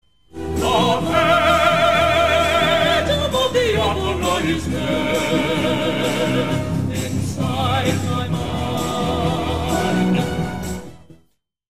Отрывок из какого мюзикла звучит в записи?